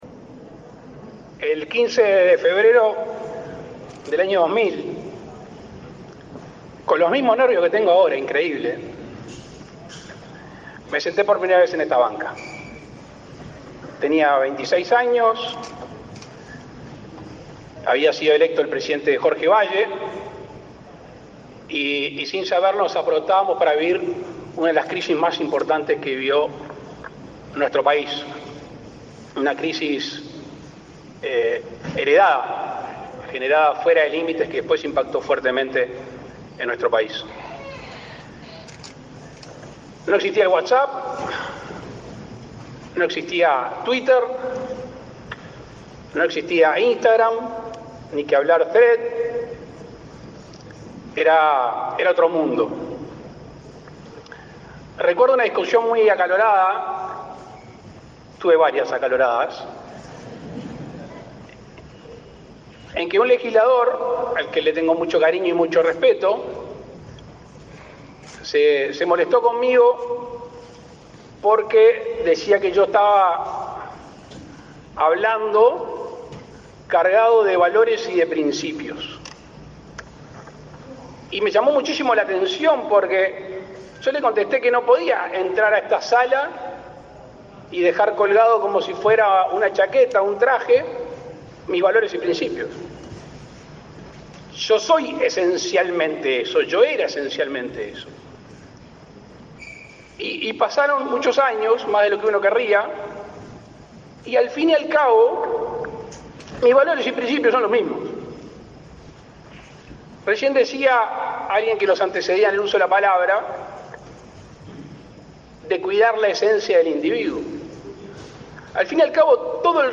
Palabras del presidente de la República, Luis Lacalle Pou
Con la presencia del presidente de la República, Luis Lacalle Pou, se realizó, este 25 de setiembre, la II Cumbre Mundial de Comisiones de Futuro 2023